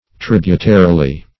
tributarily - definition of tributarily - synonyms, pronunciation, spelling from Free Dictionary Search Result for " tributarily" : The Collaborative International Dictionary of English v.0.48: Tributarily \Trib"u*ta*ri*ly\, adv. In a tributary manner.